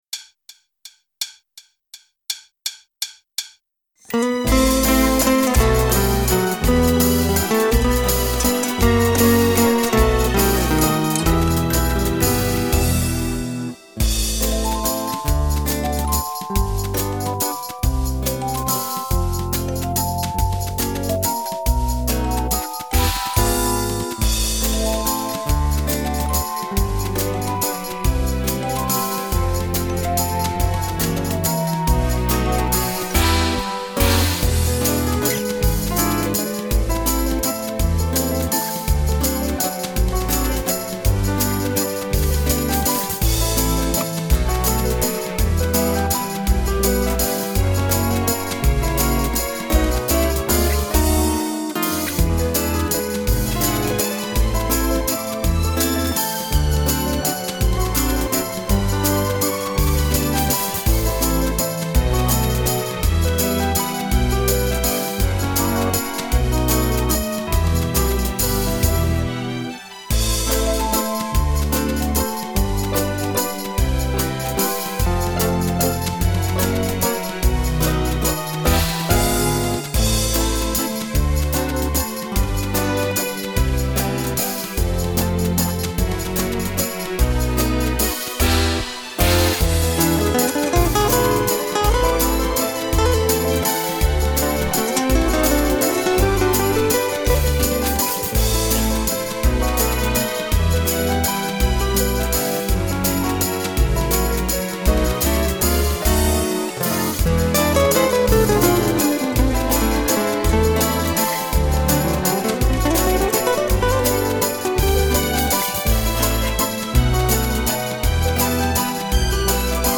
- Valse Anglaise-
LE PLAY BACK
rythmique